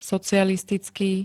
socialistický [-t-] -ká -ké príd.
Zvukové nahrávky niektorých slov